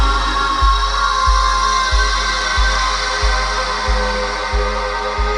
Samsung Ringtones